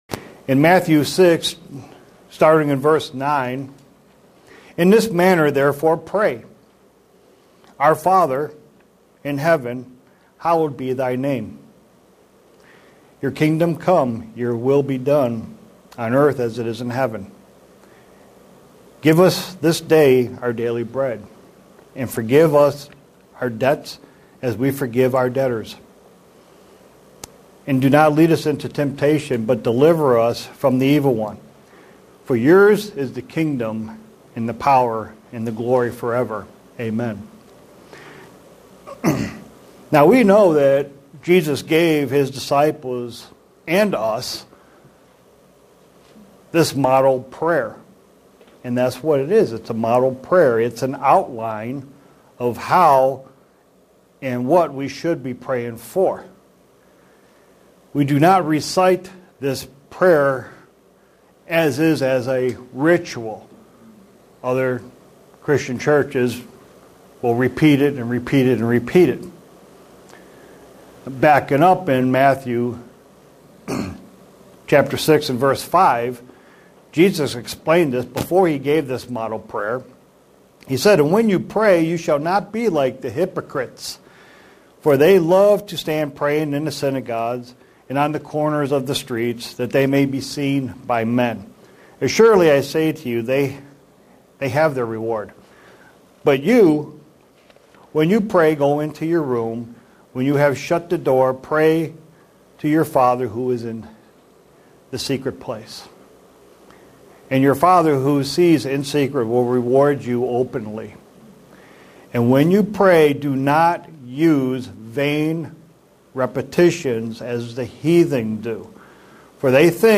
Given in Buffalo, NY
Print To focus on Forgiveness and how to apply it to our Christian Life sermon Studying the bible?